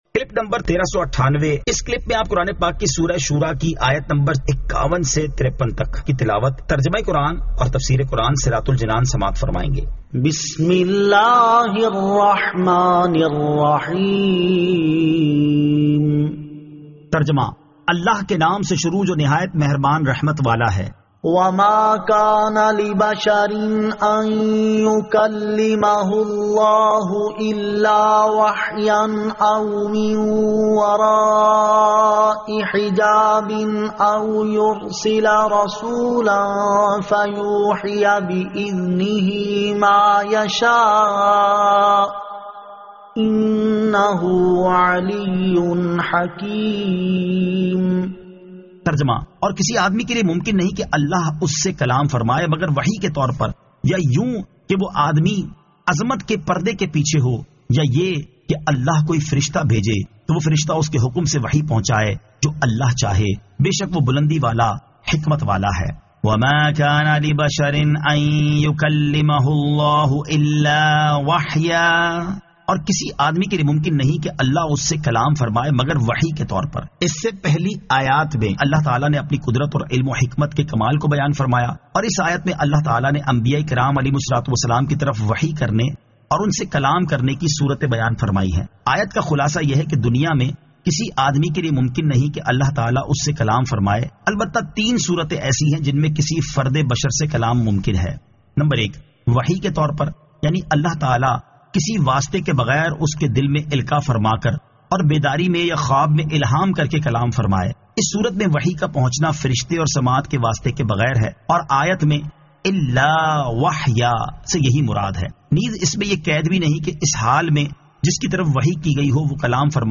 Surah Ash-Shuraa 51 To 53 Tilawat , Tarjama , Tafseer